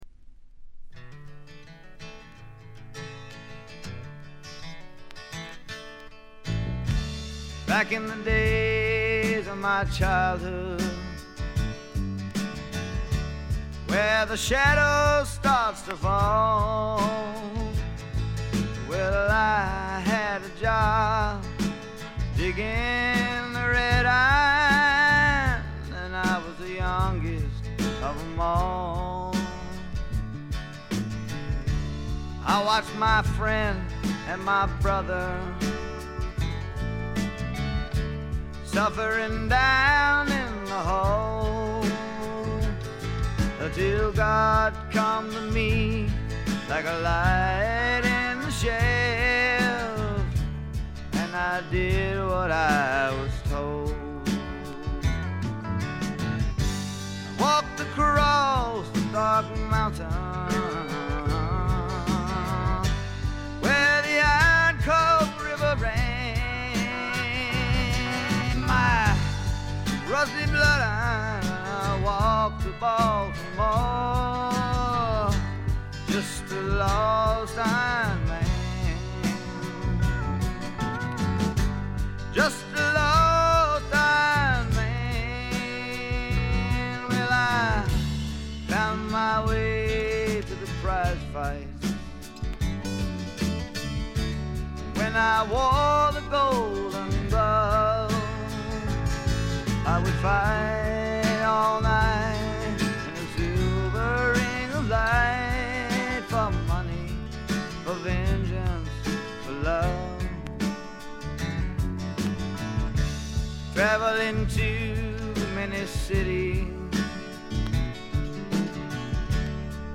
軽微なバックグラウンドノイズ、チリプチ程度。
聴くものの心をわしづかみにするような渋みのある深いヴォーカルは一度聴いたら忘れられません。
試聴曲は現品からの取り込み音源です。